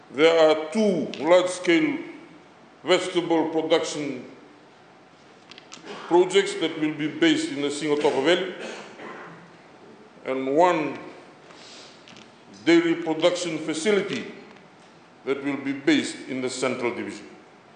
Minister for Agriculture Vatimi Rayalu shared this while delivering his budget support in Parliament.
[Minister for Agriculture Vatimi Rayalu]